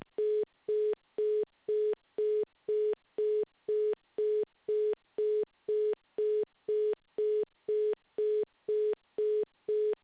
[Freeswitch-users] detecting tone
Name: hangup.wav